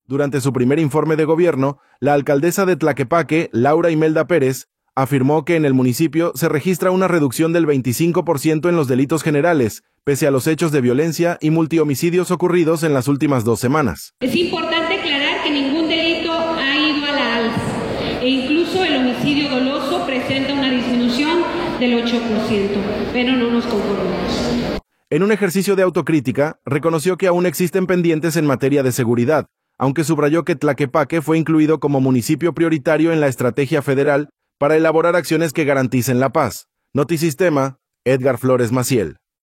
Durante su primer informe de gobierno, la alcaldesa de Tlaquepaque, Laura Imelda Pérez, afirmó que en el municipio se registra una reducción del 25 por ciento en los delitos generales, pese a los hechos de violencia y multihomicidios ocurridos en las últimas dos semanas.
informedegobierno.m4a